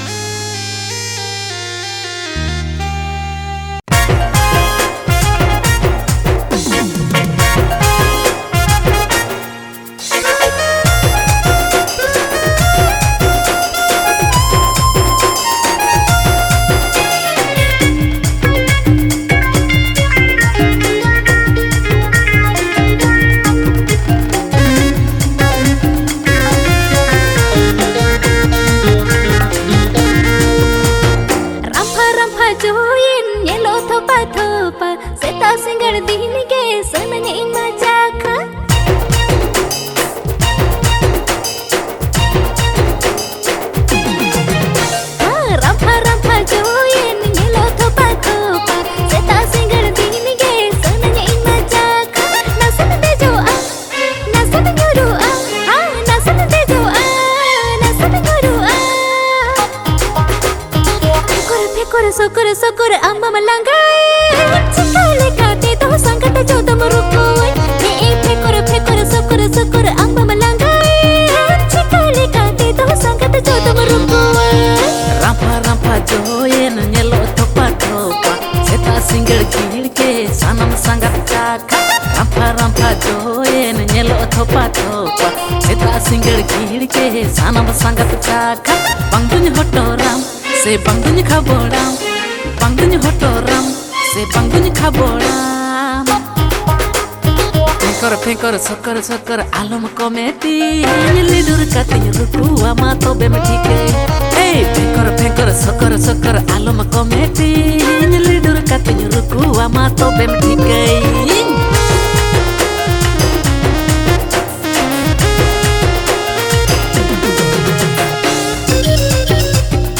Santali song